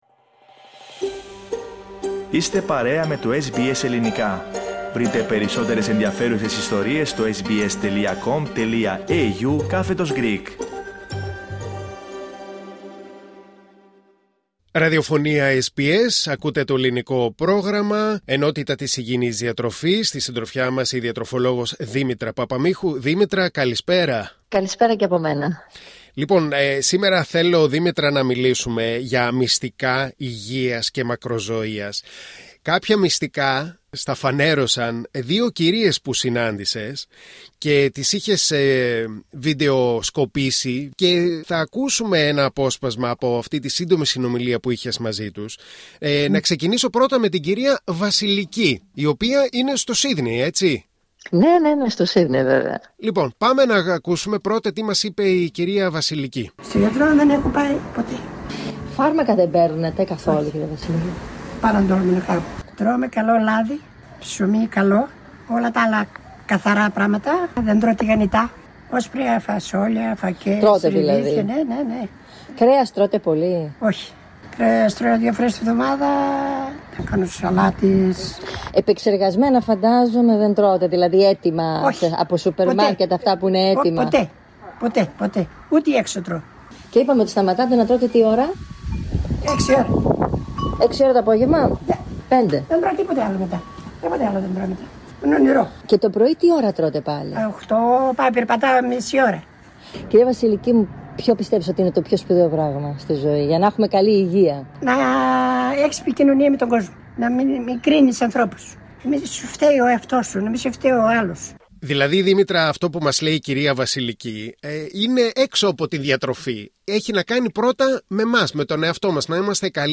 Δύο Ελληνίδες ηλικιωμένες στο Σύδνεϋ μας δίνουν συμβουλές για καλή υγεία ακόμα και σε προχωρημένη ηλικία